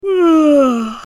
Yawn 03
Yawn_03.mp3